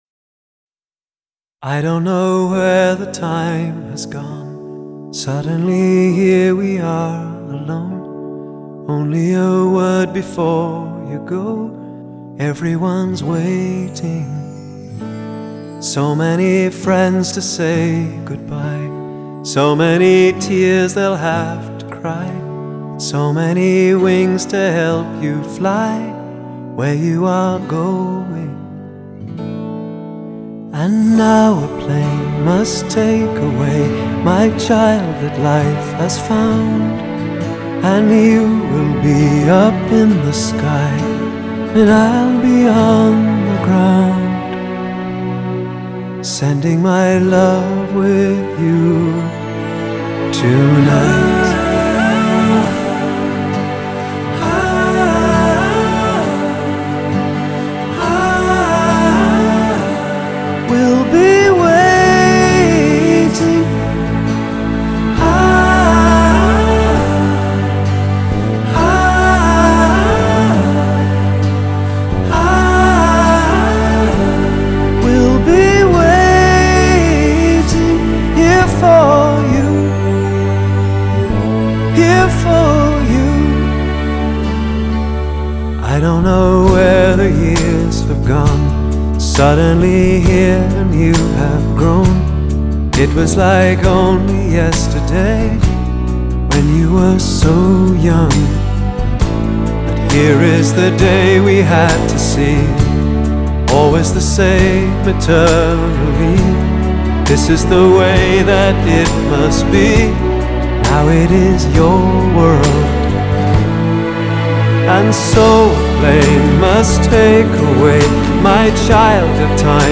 风格融合了世界音乐的精髓，包括浪漫西班牙风、神秘的居尔特风、北美洲流行风及东欧优雅古味；